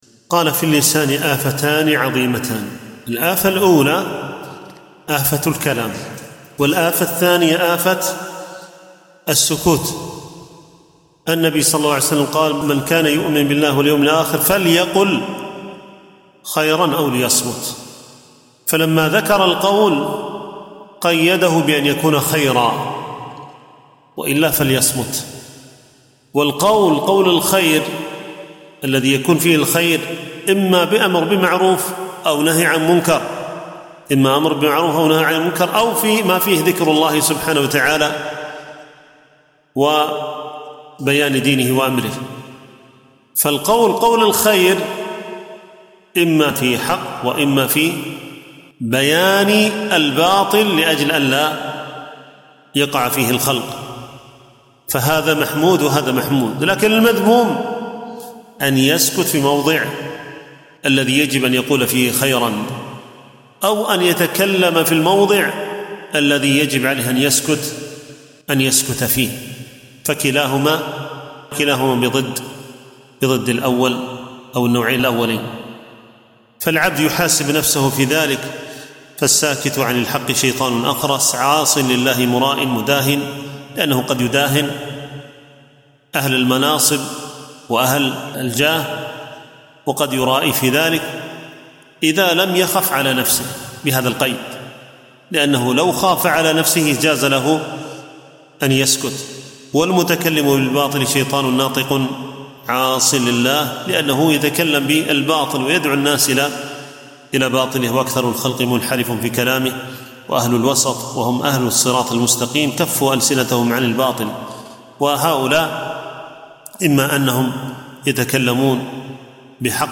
التنسيق: MP3 Mono 48kHz 102Kbps (VBR)